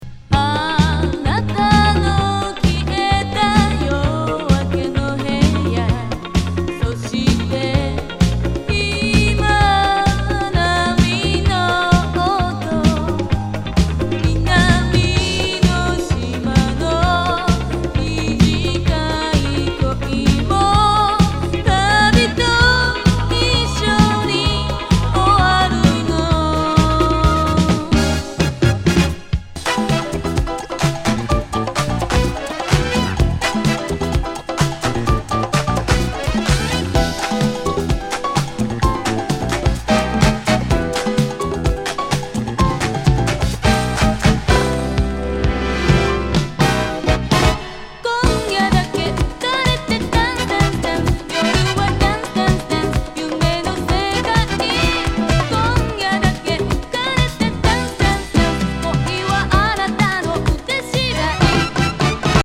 with OBI） Japanese,Soul,Disco,Vocal ♪LISTEN LABEL/CAT.NUMBER